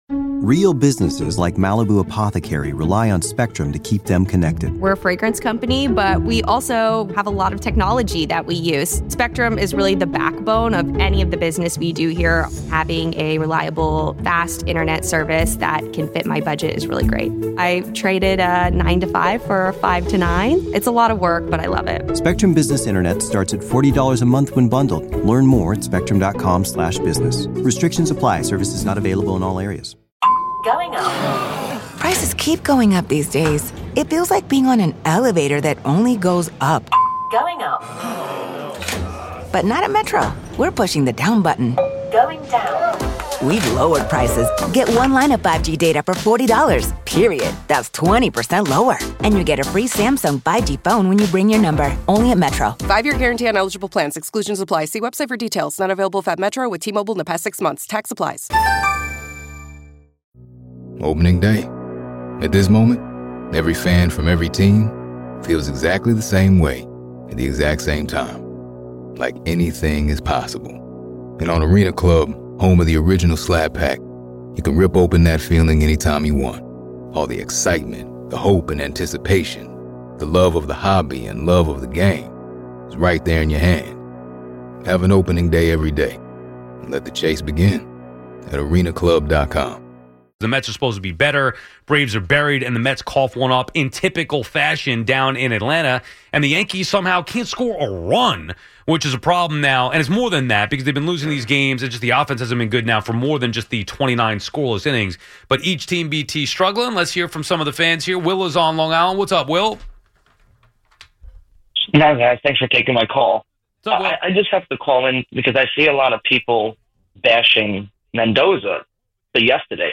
From managerial blunders to players who couldn't hit water if they fell out of a boat, no sacred cow is safe from their fiery, sarcastic rants.